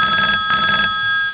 phone.wav